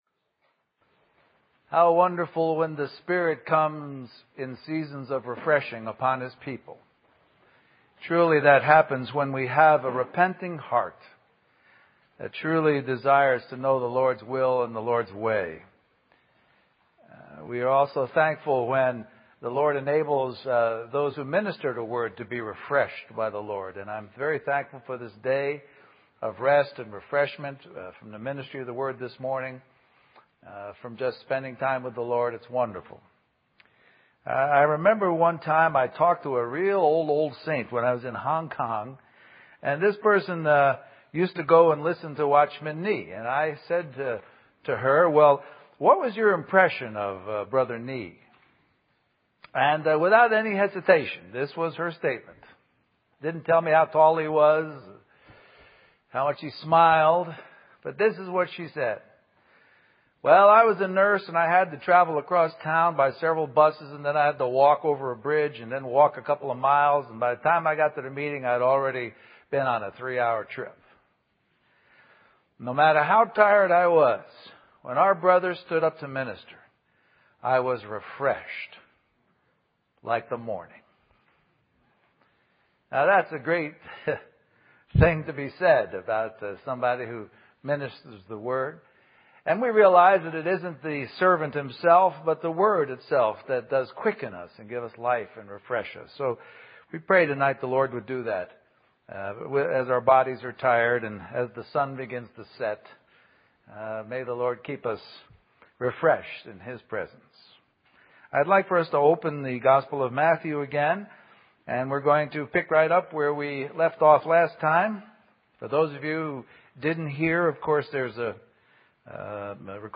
A collection of Christ focused messages published by the Christian Testimony Ministry in Richmond, VA.
Western Christian Conference